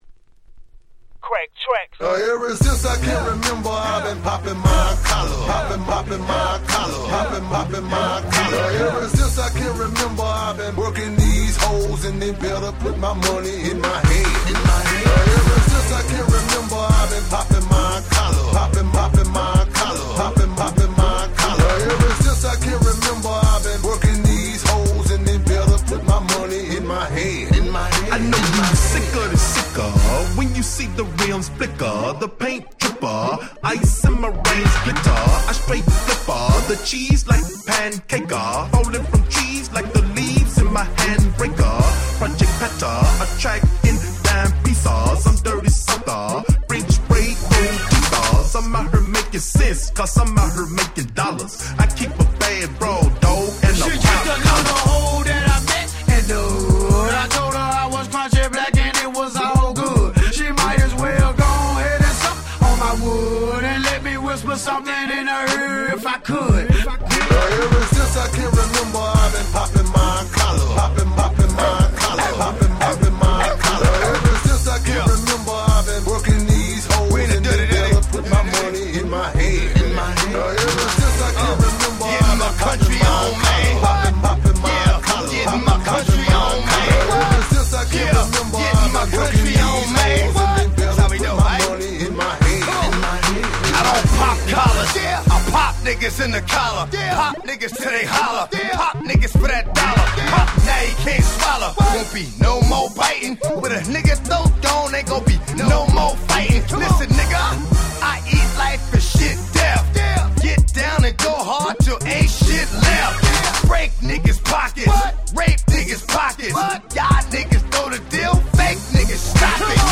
05' Super Hit Southern Hip Hop !!